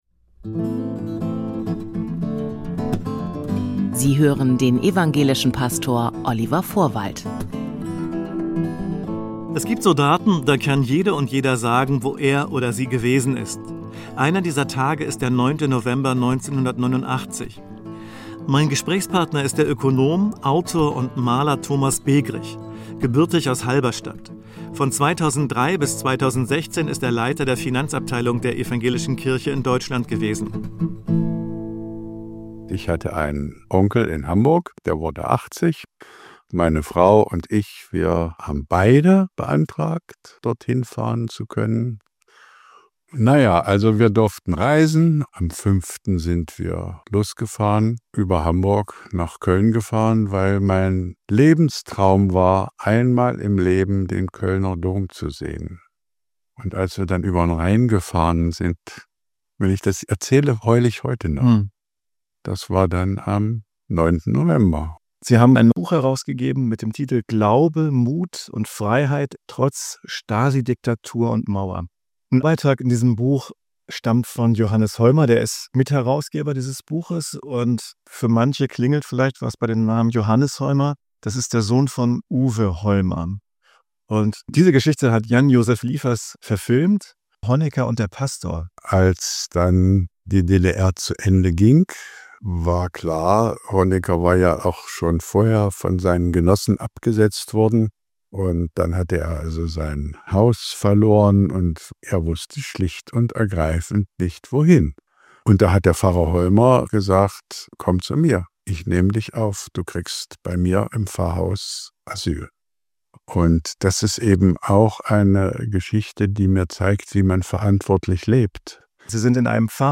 spricht mit dem Ökonom, Autor und Maler.